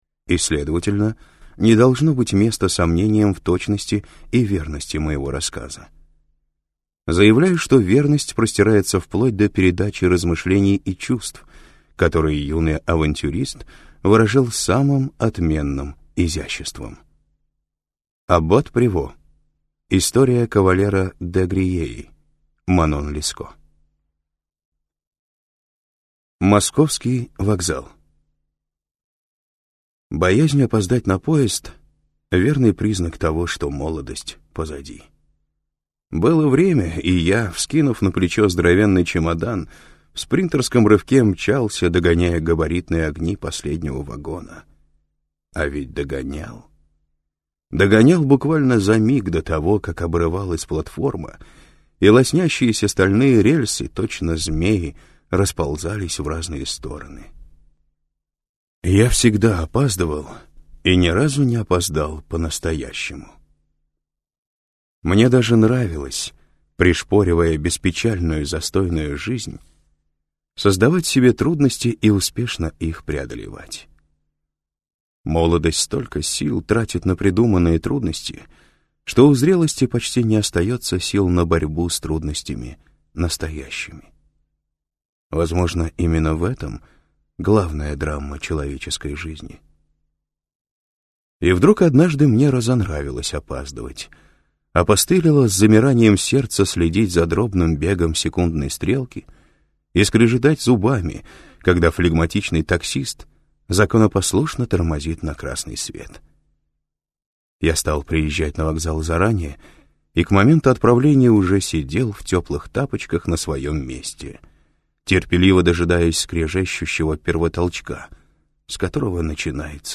Аудиокнига Небо падших | Библиотека аудиокниг